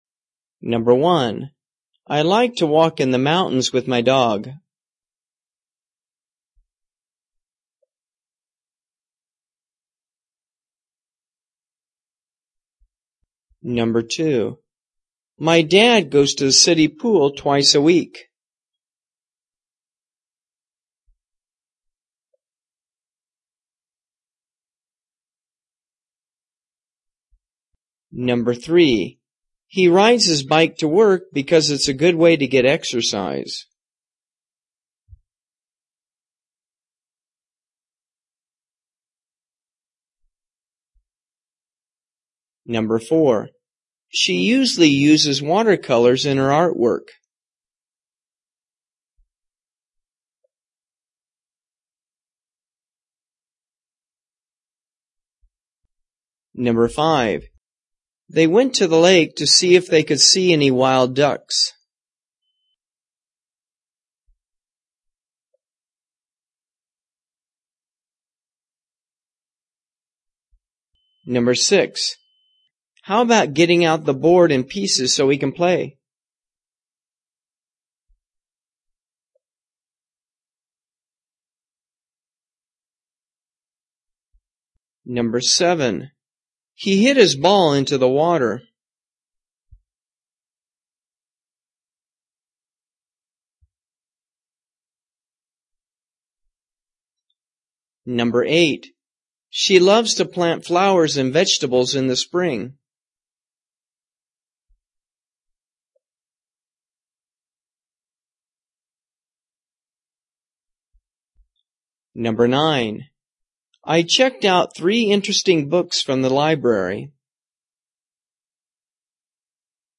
【听英文对话做选择】爱好 听力文件下载—在线英语听力室